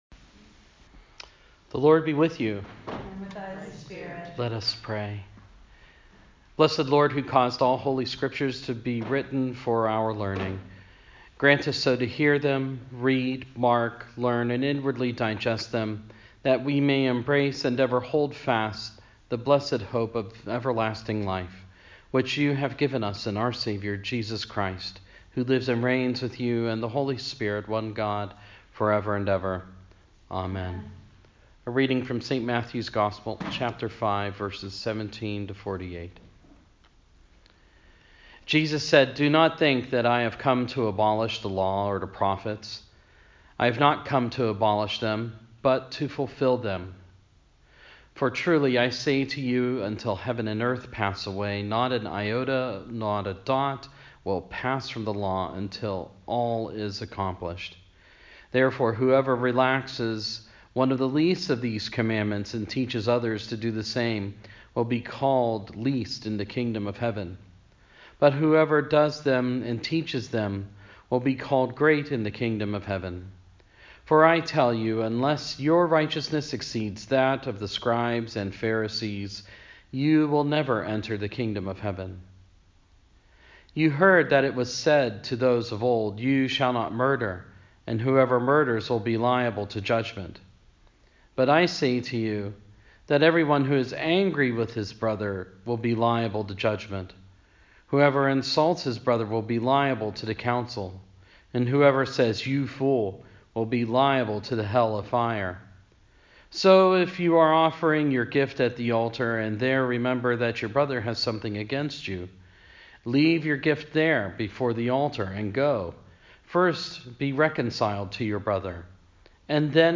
Yesterday, for our Friday Lesson and Litany at noon, we reflected on the Matthew 5:17-48 and prayed.
sermon-on-the-mount-2.mp3